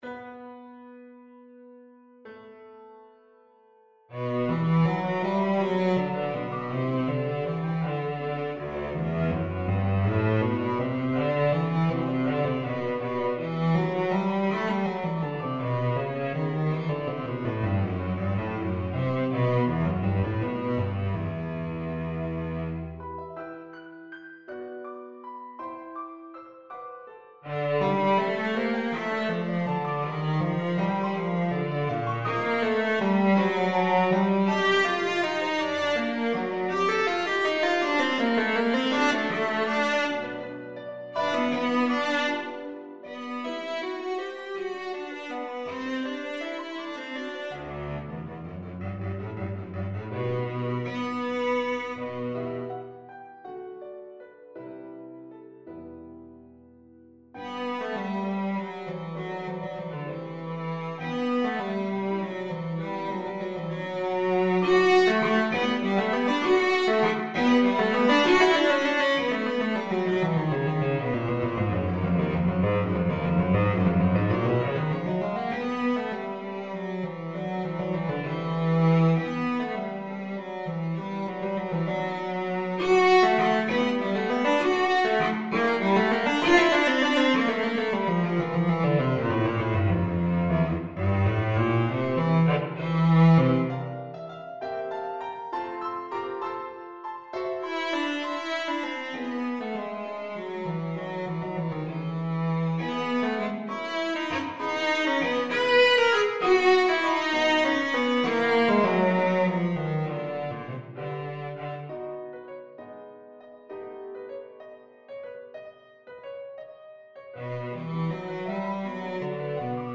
6/8 (View more 6/8 Music)
Classical (View more Classical Cello Music)